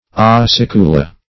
Acicula \A*cic"u*la\, n.; pl. Acicul[ae]. [L., a small needle,